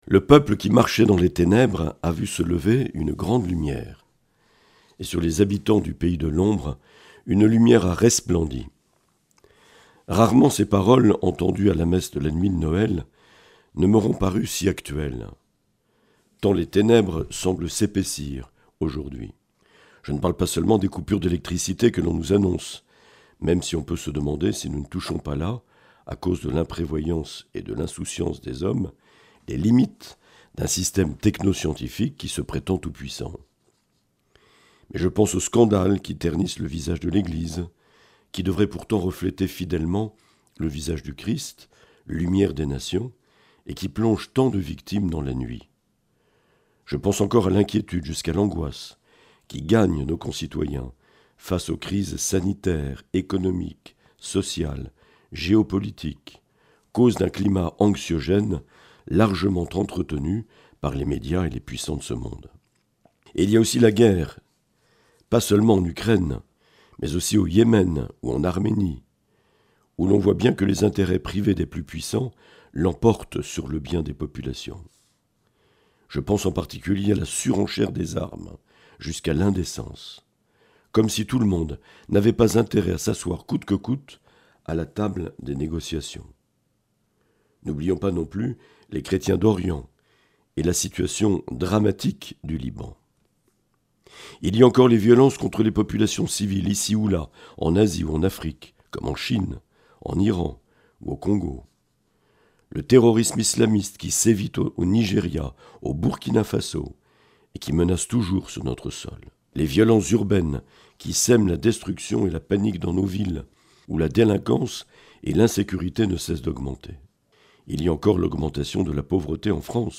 Mgr Marc Aillet, évêque de Bayonne, Lescar et Oloron.